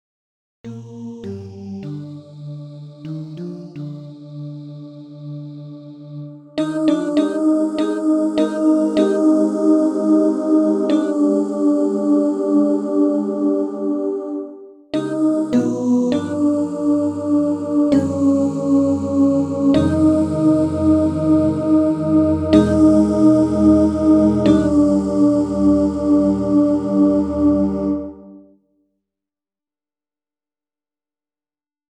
Key written in: E♭ Major